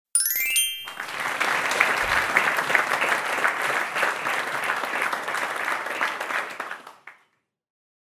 Tiếng vỗ tay trả lời Đúng
Hiệu Ứng Tiếng Vỗ Tay Trả Lời Đúng
Âm thanh tiếng vỗ tay trả lời đúng là sự kết hợp hoàn hảo giữa tiếng chuông báo hiệu và sự tán thưởng nhiệt liệt. Đây là hiệu ứng không thể thiếu khi edit video dạng gameshow, trắc nghiệm kiến thức hoặc các video giáo dục nhằm khích lệ người xem. Âm thanh này giúp tạo không khí hào hứng và khẳng định sự thành công cho mỗi câu trả lời chính xác.